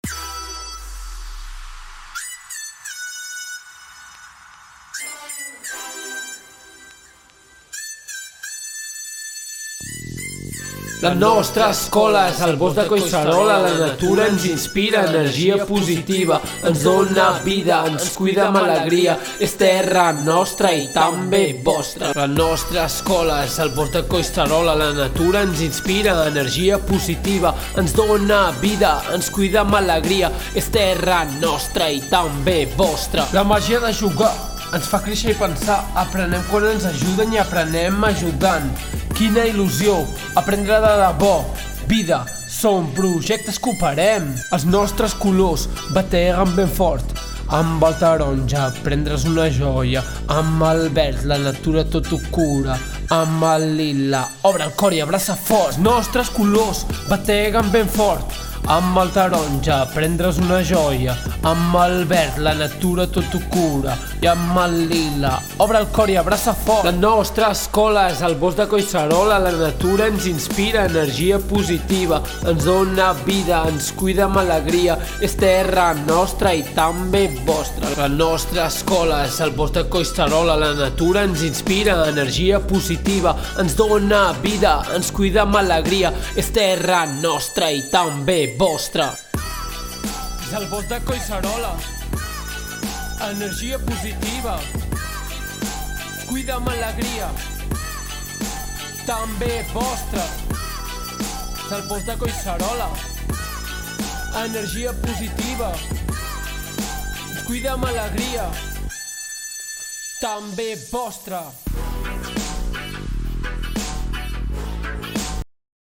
Com ja sabeu a la nostra escola tenim un himne que van escriure els nens i nenes de l’escola fa uns quants cursos. Aquest any hem decidit actualitzar-lo i convertir-lo en un rap!!
l’hem cantat a la Festa Major
RAP-HIMNE-MESTRE-MORRERA.m4a